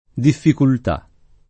difficultà [ diffikult #+ ]